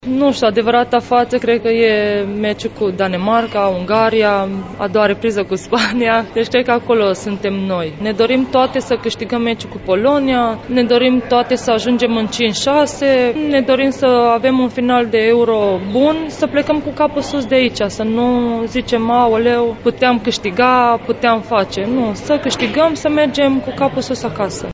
Declarațiile a două dintre tricolore